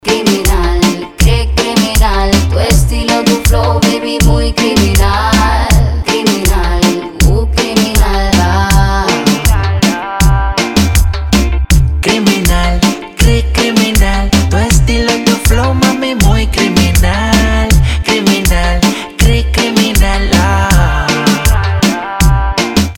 • Качество: 320, Stereo
ритмичные
мужской вокал
женский вокал
заводные
Reggaeton
Latin Pop